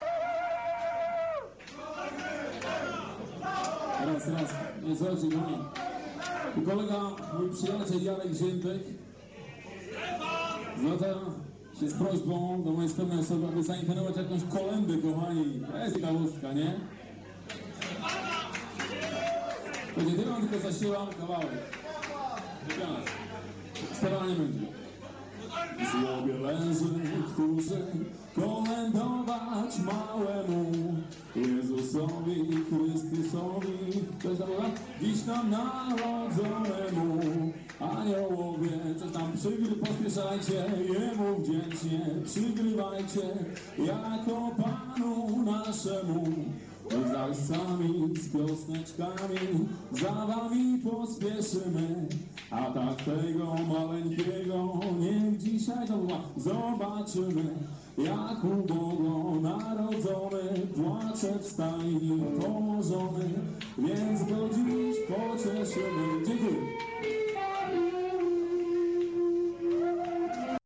kONCERT SWIATECZNY
Klub IKS (dawniej Wysepka), 22/12/1999
Jakosc nagran bootlegowa!